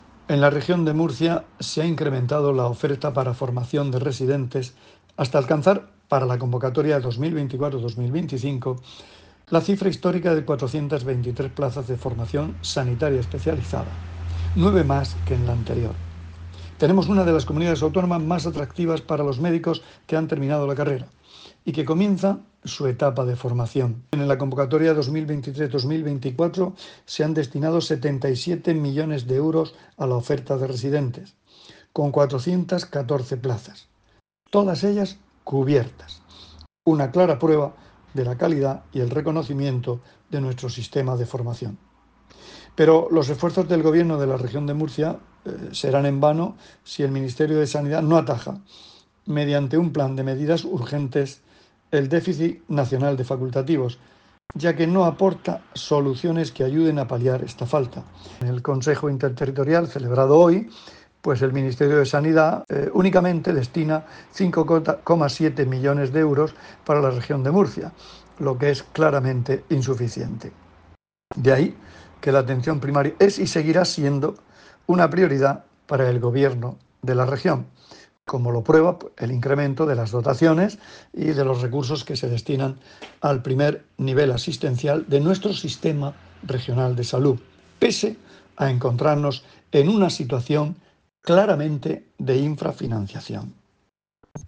Declaraciones del consejero de Salud, Juan José Pedreño, tras la celebración hoy del Consejo Interterritorial de Salud.